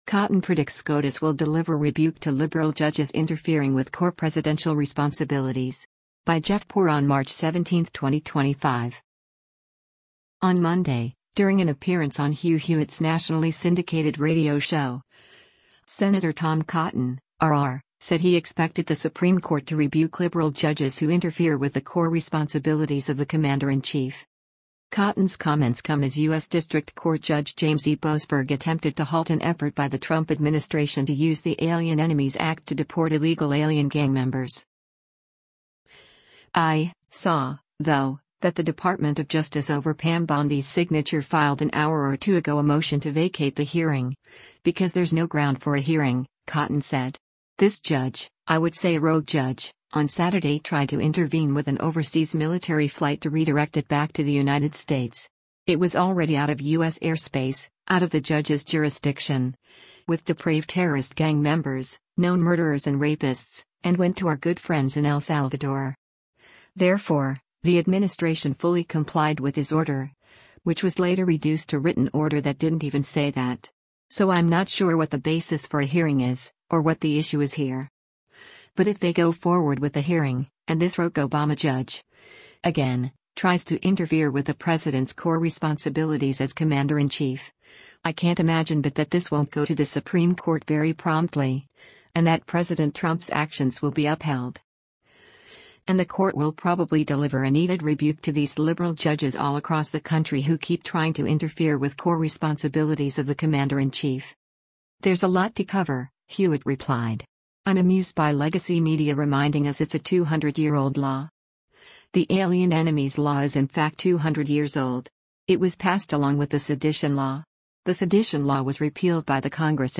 On Monday, during an appearance on Hugh Hewitt’s nationally syndicated radio show, Sen. Tom Cotton (R-AR) said he expected the Supreme Court to rebuke liberal judges who interfere with the “core responsibilities of the commander-in-chief.”